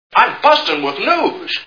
Mr Smith Goes to Washington Movie Sound Bites